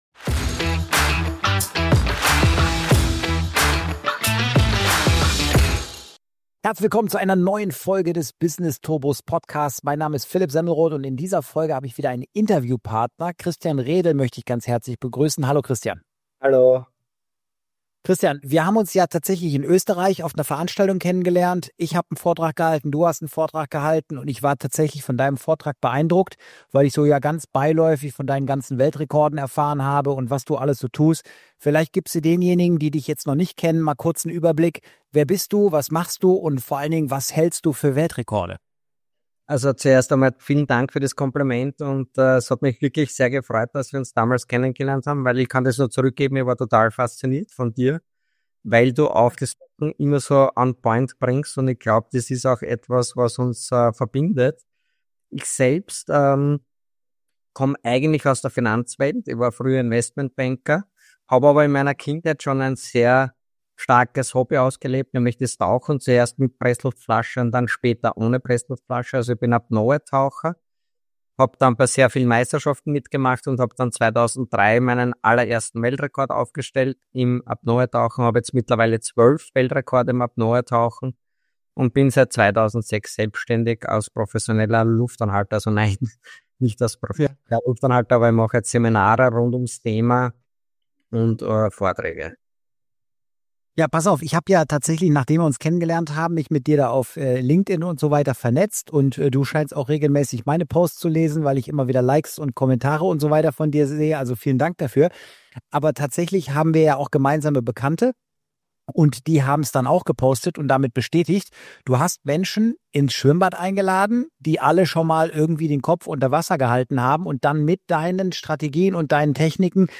#048 - INTERVIEW